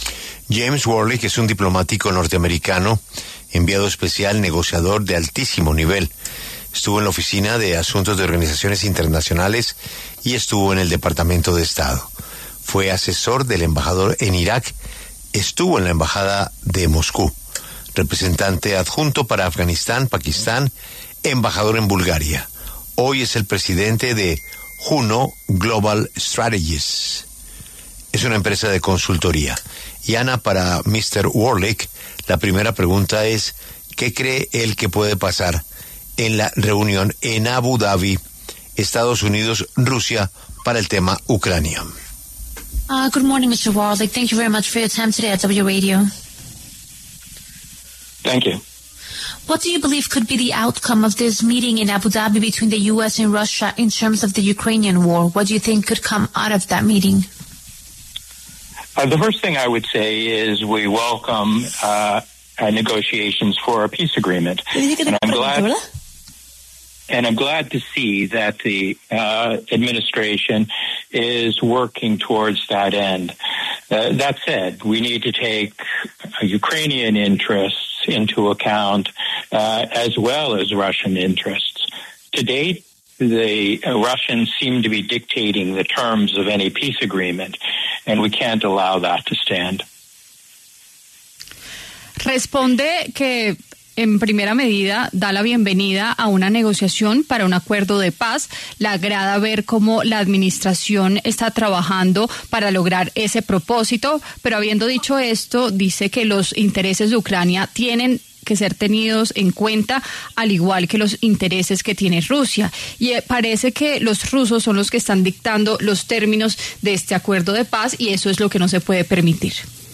En esa línea, en los micrófonos de La W, con Julio Sánchez Cristo, habló James Warlick, diplomático estadounidense con décadas de experiencia como enviado especial, negociador y asesor de alto nivel en el Gobierno, quien analizó la reunión.